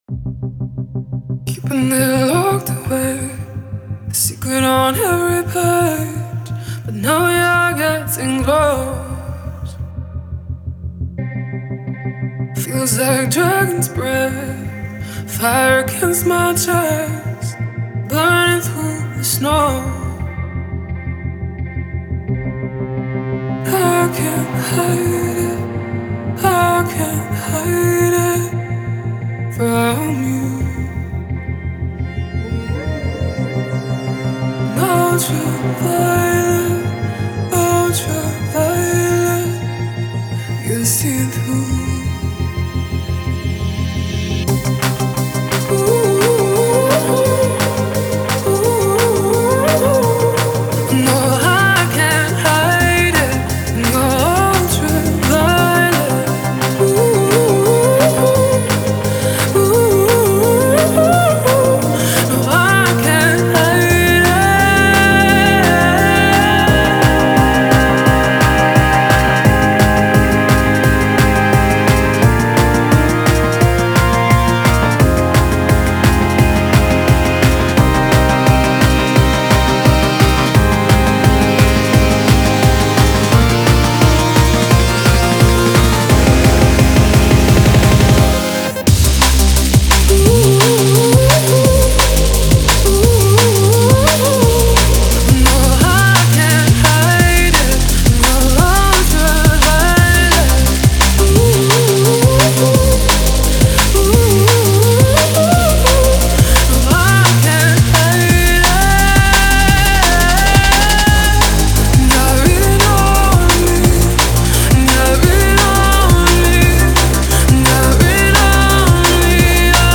Файл в обменнике2 Myзыкa->Drum'n'Bass
Стиль: Drum & Bass